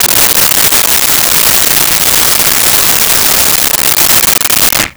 Blender On Whip
Blender on Whip.wav